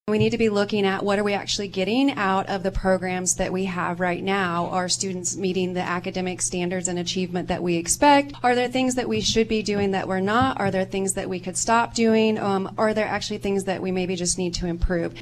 Rising property taxes was a point of emphasis at the USD 383 candidate forum, hosted by the League of Women Voters Saturday.